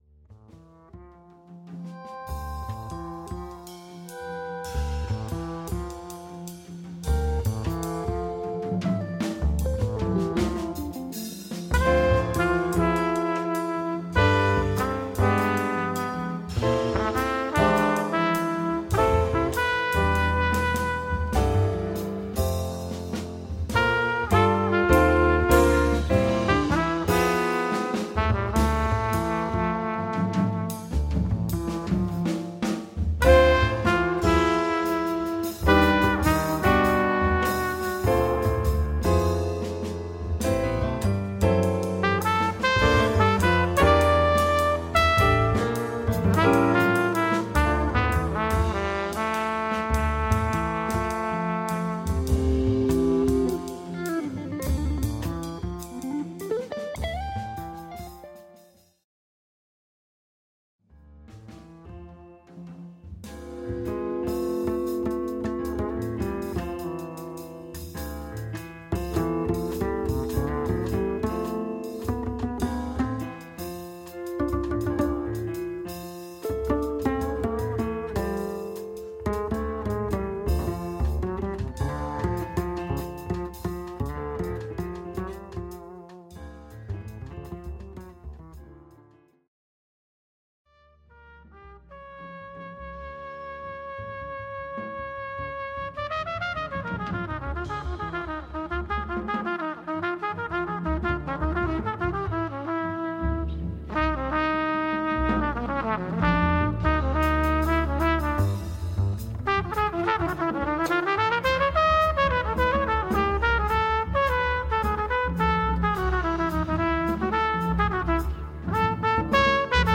my experience with jazz and other acoustic music